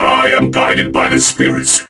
robo_bo_start_vo_03.ogg